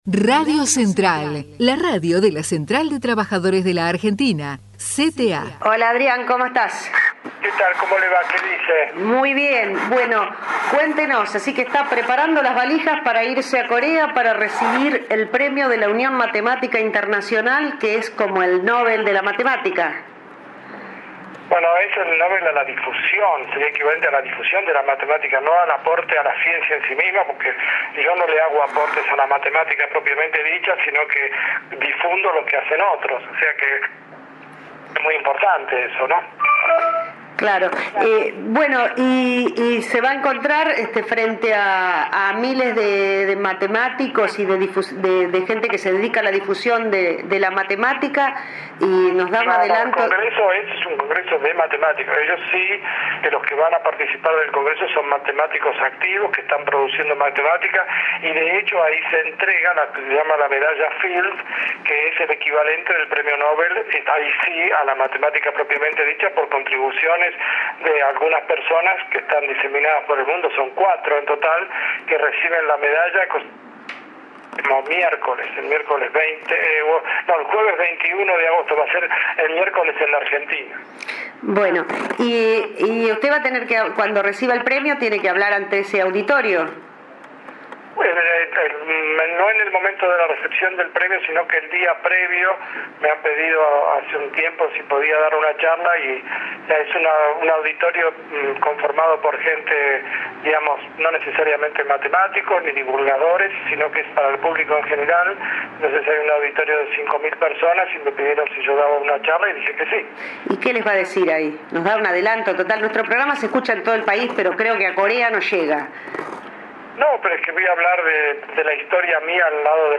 El prestigioso matemático entrevistado en el programa "CONADU en el medio"